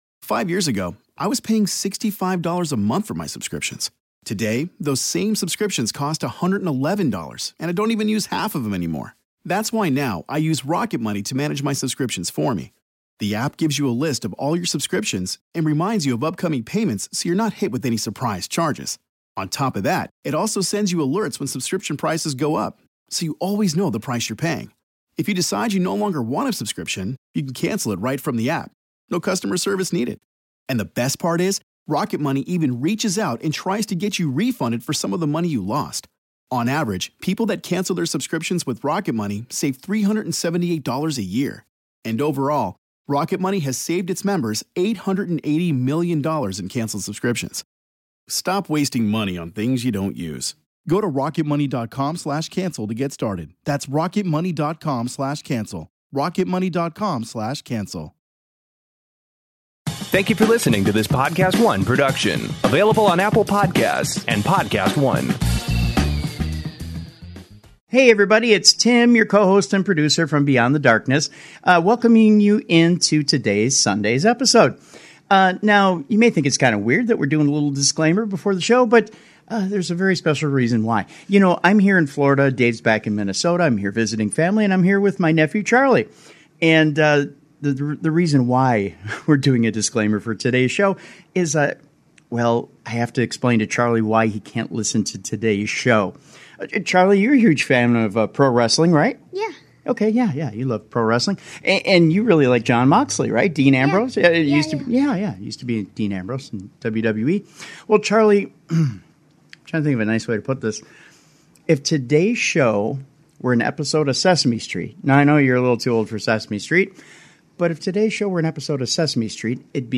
This was recorded live from the Stardust Lounge aboard the Norwegian Pearl!
The language got very saucy!